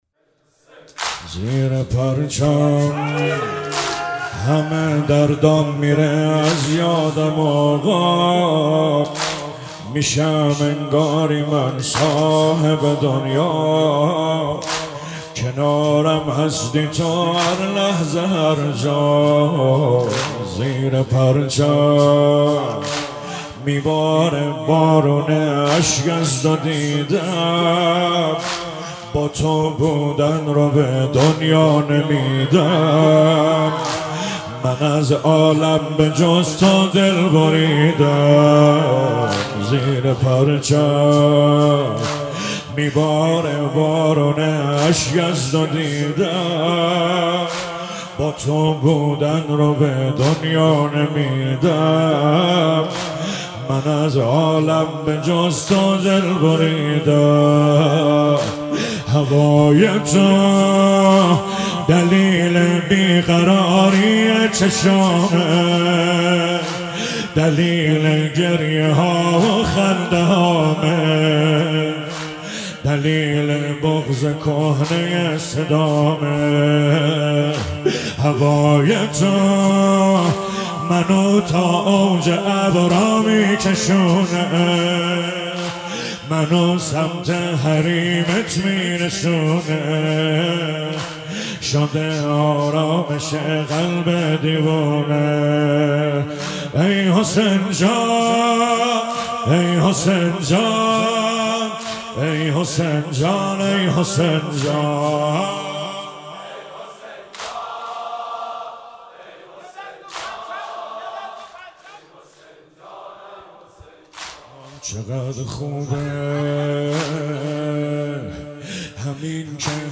محرم 98 شب چهارم - واحد